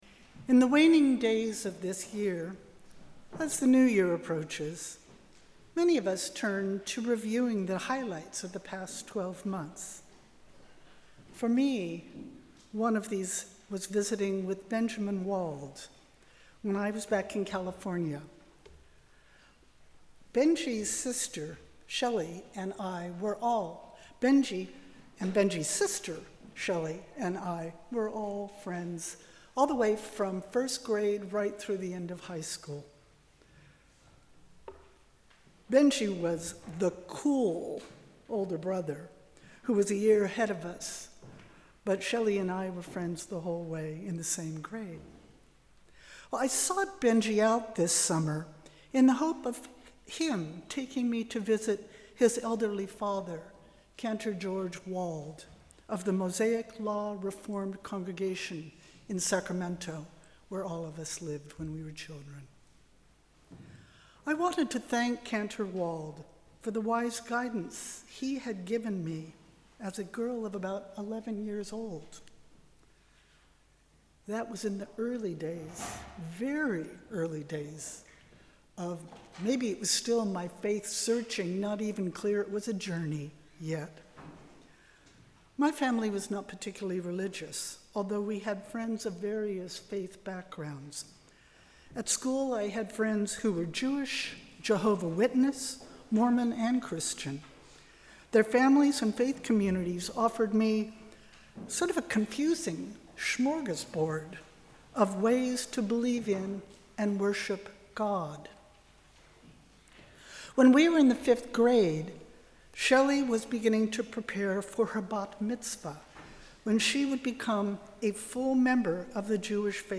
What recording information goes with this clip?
Sermon on the First Sunday of Christmas, 2024